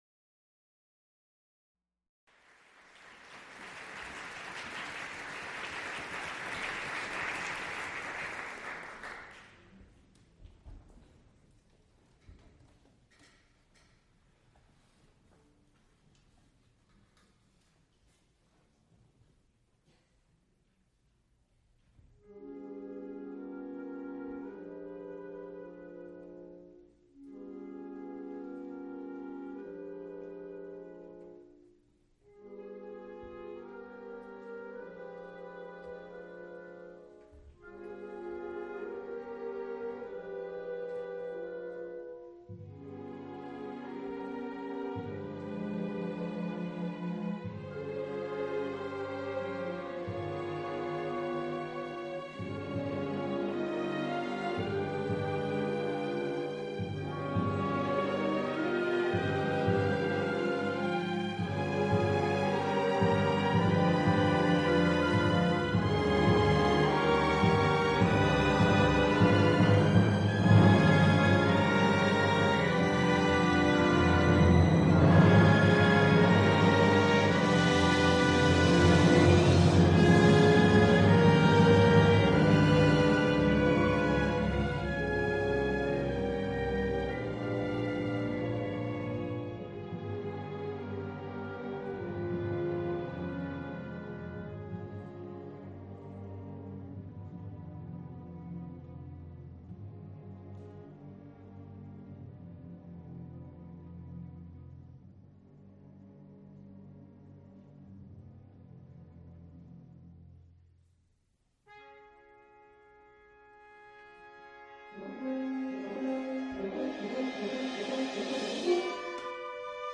Recorded live February 23, 1987, Bellefield Annex, University of Pittsburgh.
musical performances
Orchestral music